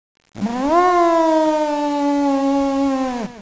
elephant.wav